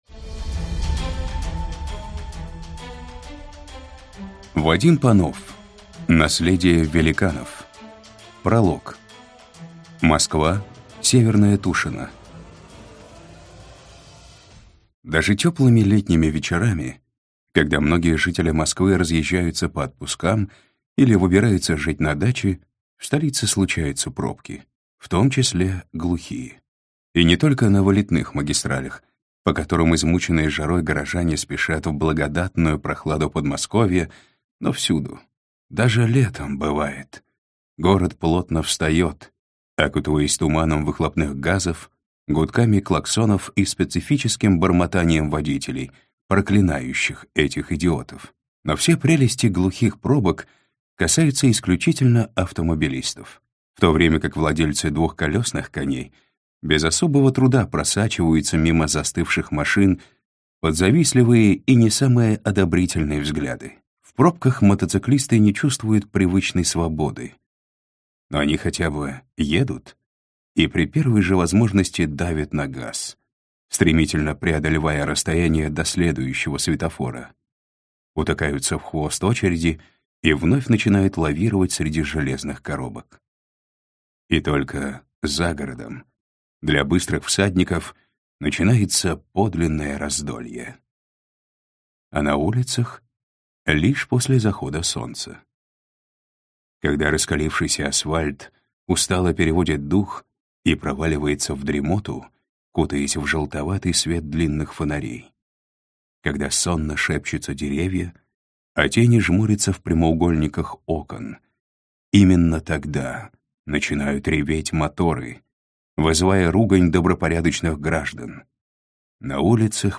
Аудиокнига Наследие великанов - купить, скачать и слушать онлайн | КнигоПоиск